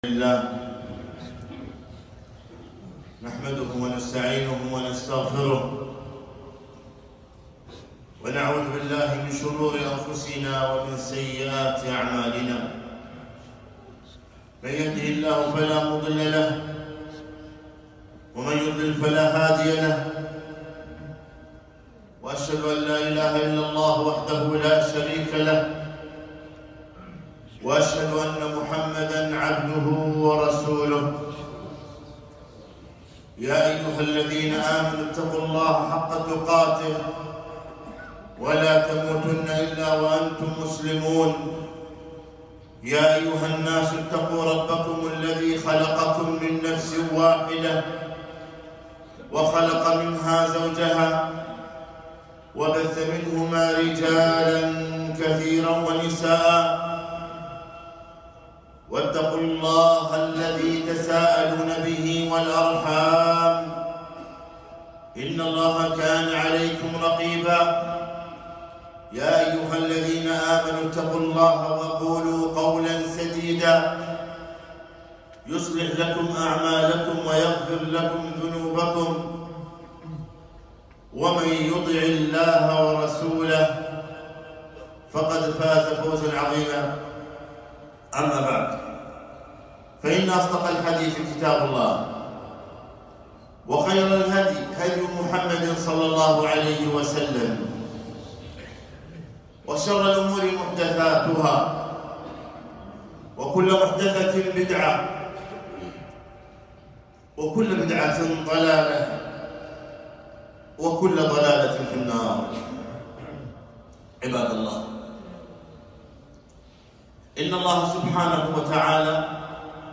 خطبة - انتبه أنت في اختبار